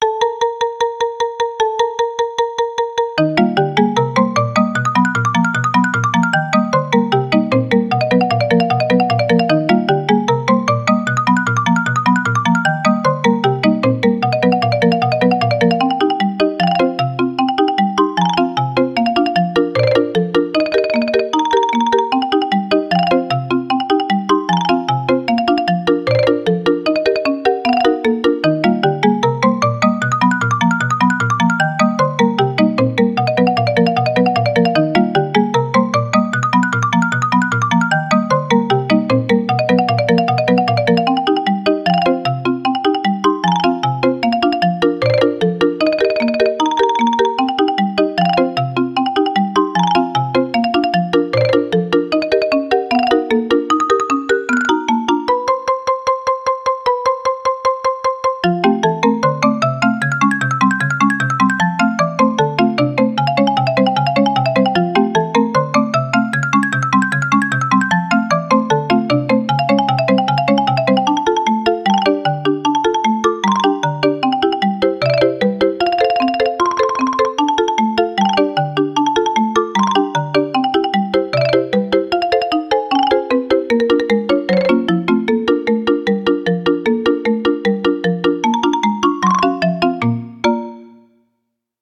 ogg(R) 軽やか マリンバ 疾走
可愛く騒がしい場面に。